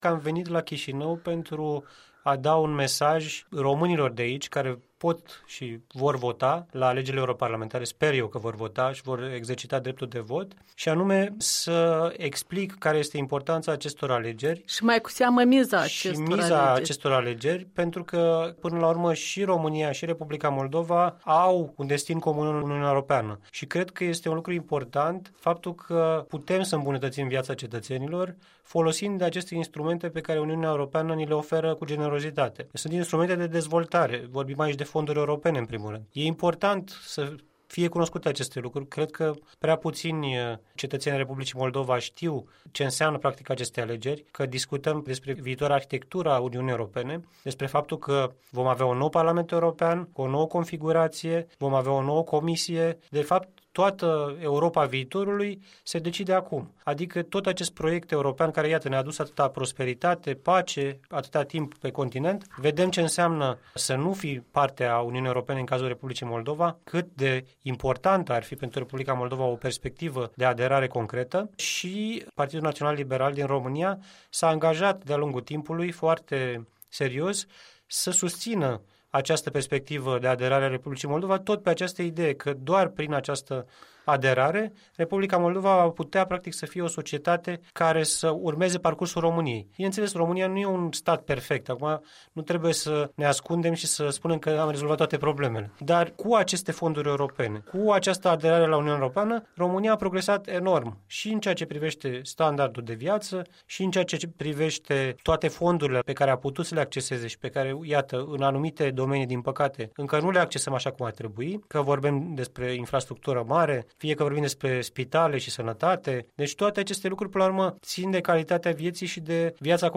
Un interviu cu deputatul PNL, membru al Camerei Deputaților de la Bucureşti.